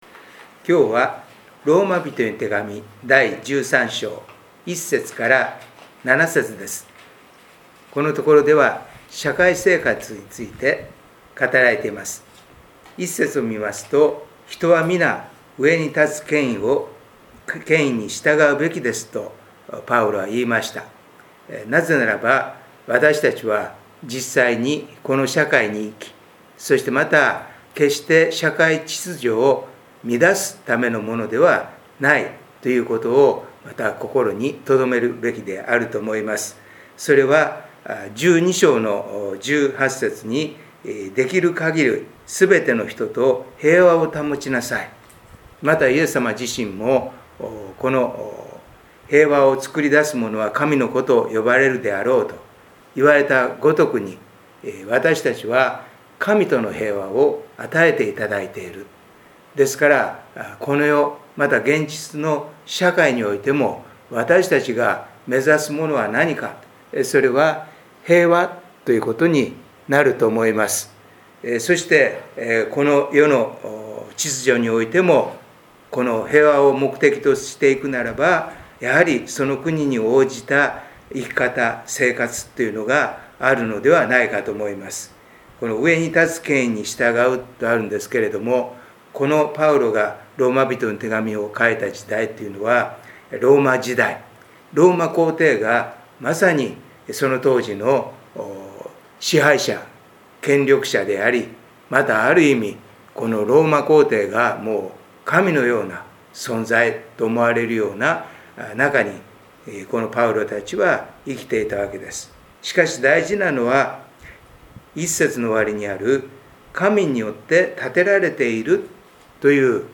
カナン宣教デー礼拝